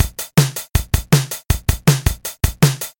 「Linn LM-1 Drum Computer」を音をエミュレートしたドラムマシンプラグインです。
・ROM 2 9000 バージョンに近い
■ ROM2で「MininnDrum」を再生したパターン